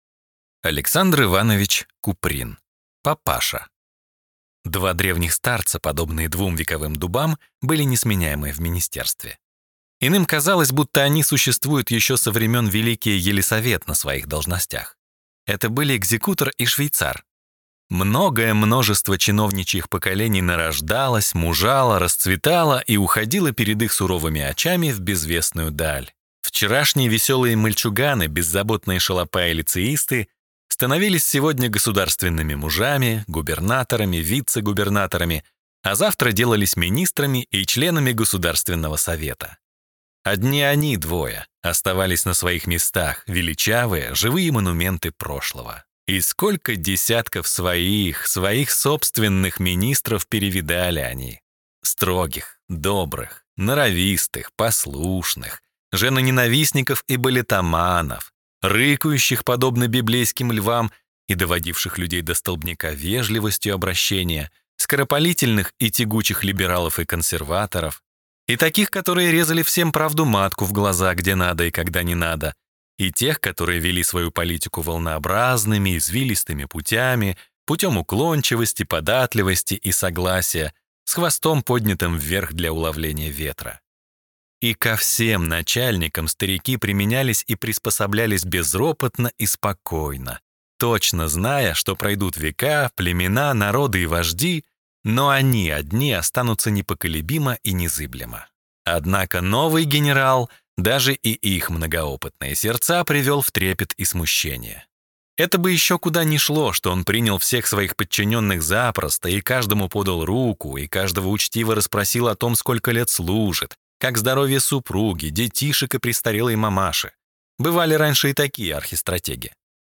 Aудиокнига Папаша